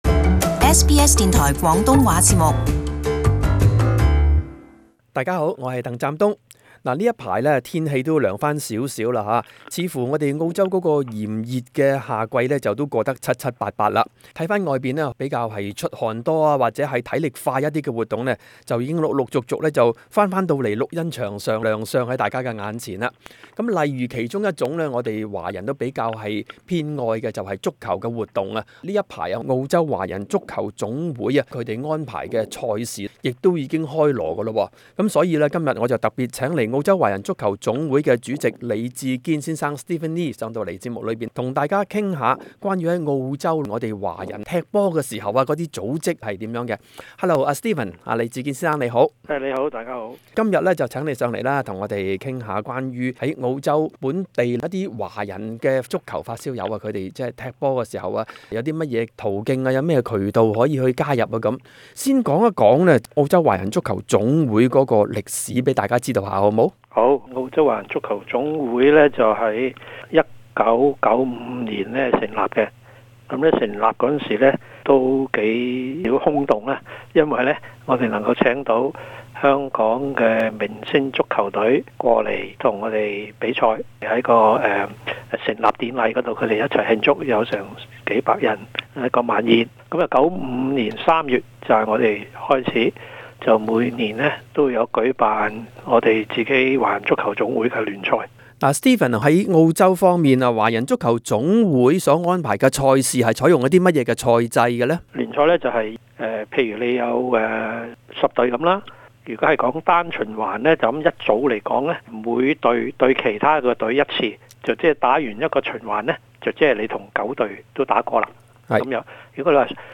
【社區專訪】澳洲華人的冬季體育活動 - 足球